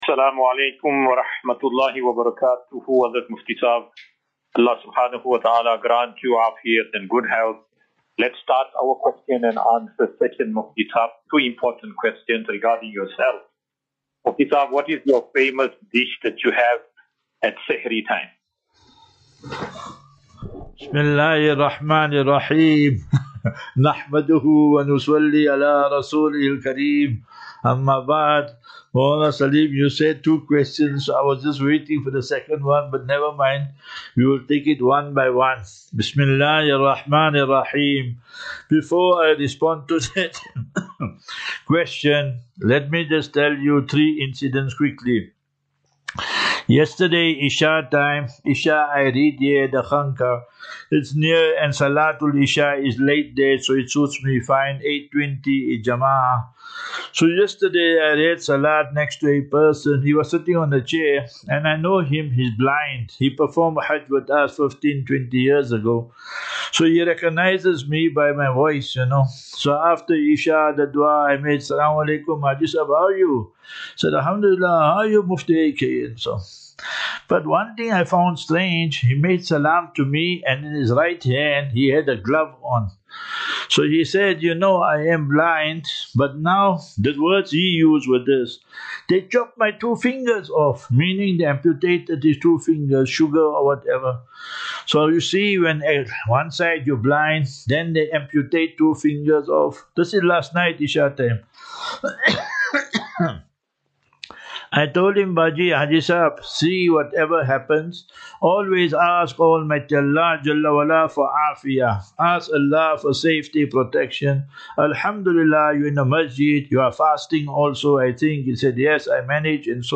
Assafinatu Illal - Jannah. QnA